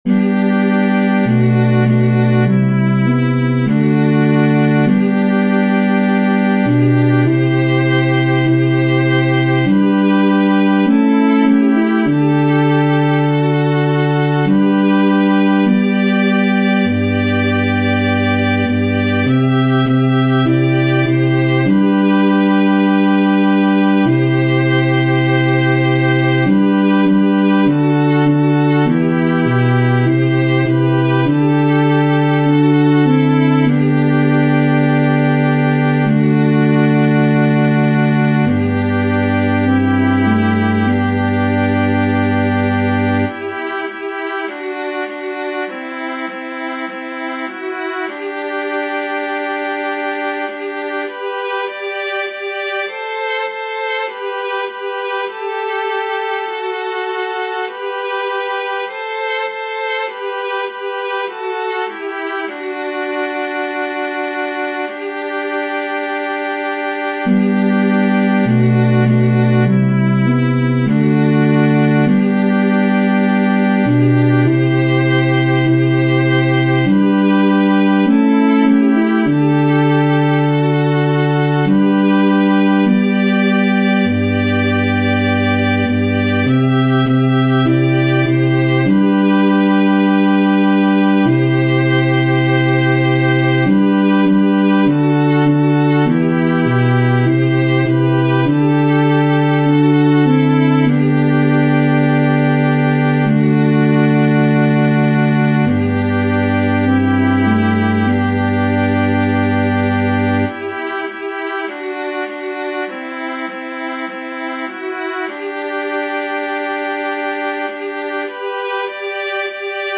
• Catégorie : Antienne d'ouverture